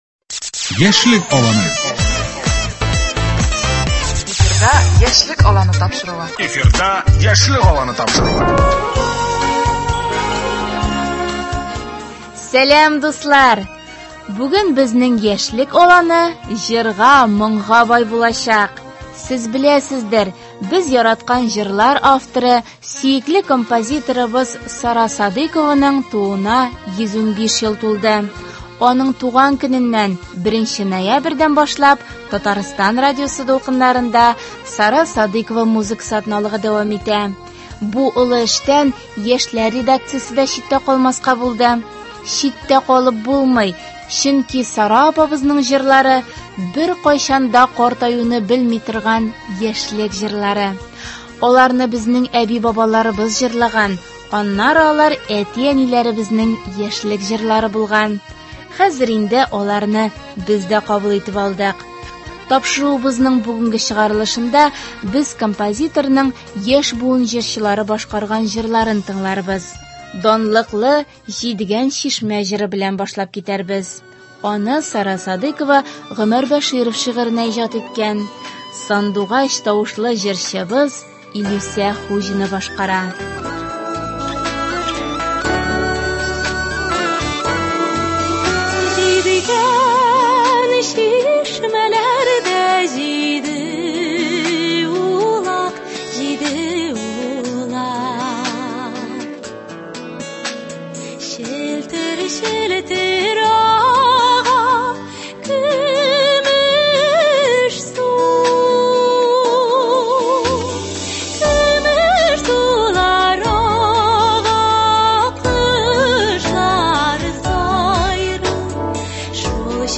Тапшыруыбызның бүгенге чыгарылышында без композиторның яшь буын җырчылары башкарган җырларын тыңларбыз.